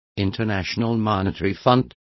Also find out how FMI is pronounced correctly.